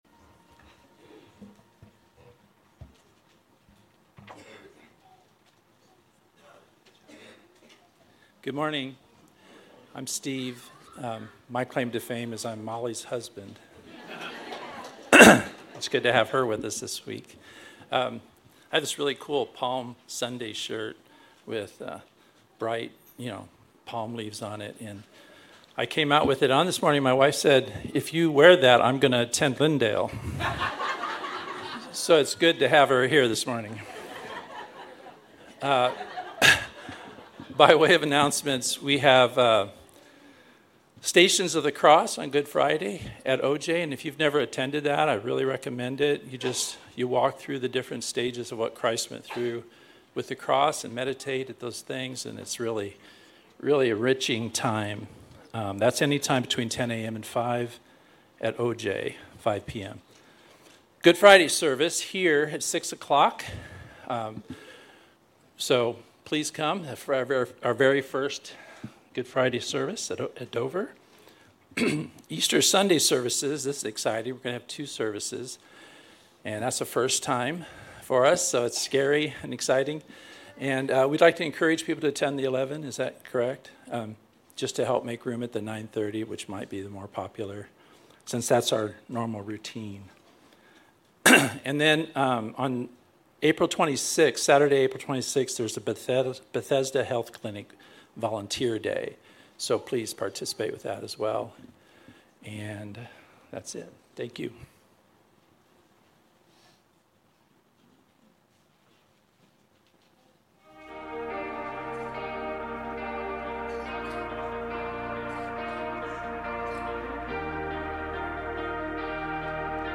Grace Community Church Dover Campus Sermons 4_13 Dover Campus Apr 14 2025 | 00:28:44 1x 00:00 / 00:28:44 Subscribe Share RSS Feed Share Link Embed